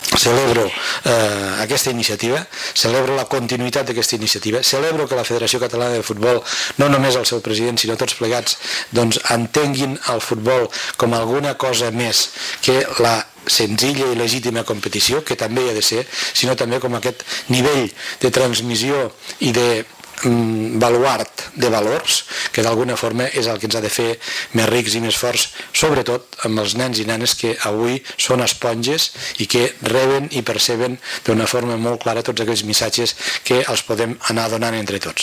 La sala d’actes de la Diputació de Lleida ha estat l’escenari de la presentació d’aquesta campanya que pretén eradicar la violència a l’entorn del futbol i que coincideix amb el treball d’adequació del reglament i dels estatuts que realitza la Federació Catalana de Futbol per introduir aquestes conceptes contra la violència. En la seva intervenció, el president de la Diputació de Lleida, Joan Reñé, ha celebrat aquesta iniciativa amb la que es deixa clar que el futbol, a més d’una competició, és un esport transmissor de valors com el respecte i la tolerància. També ha destacat la importància que aquest missatge arribi als més petits, per a que l’assimilin com a propi ja des de petits.